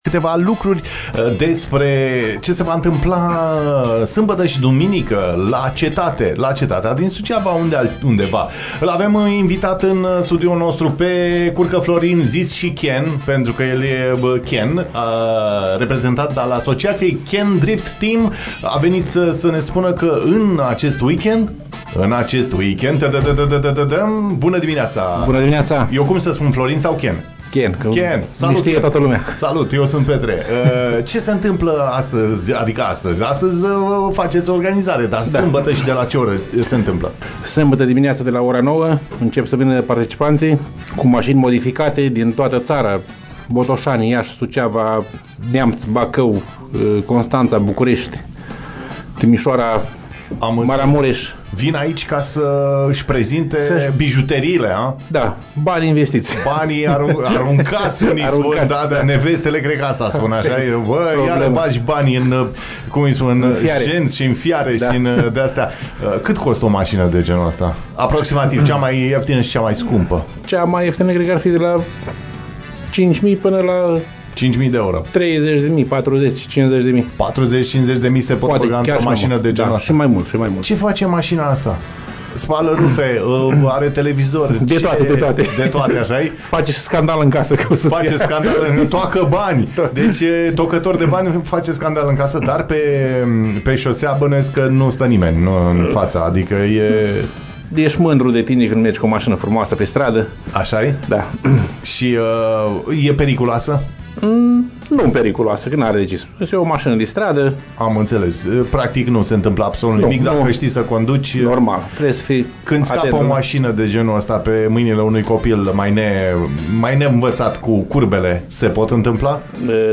ne-a spus live la ce să ne așteptăm la final de săptămână!